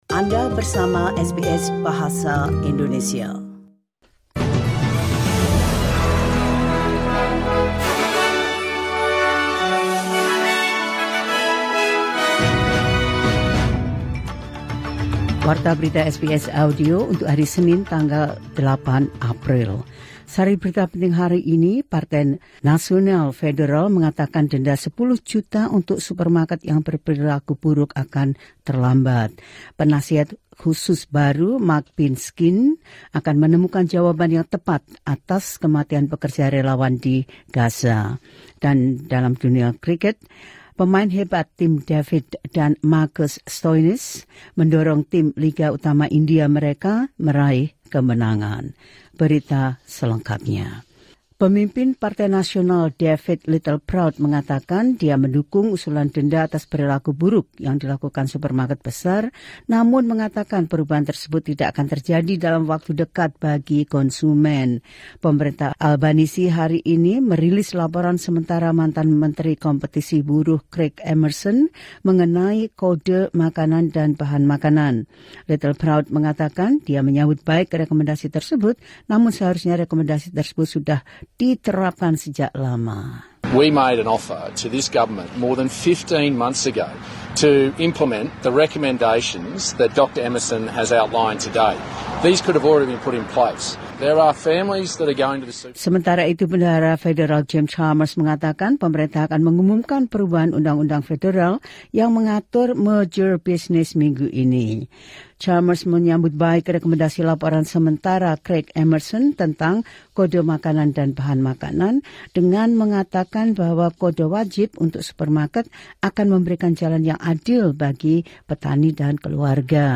The latest news of SBS Audio Indonesian program – 08 Apr 2024